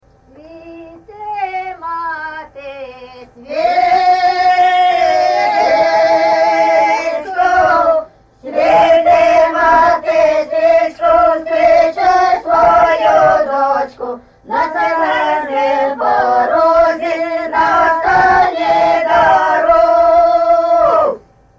ЖанрВесільні
Місце записус. Лука, Лохвицький (Миргородський) район, Полтавська обл., Україна, Полтавщина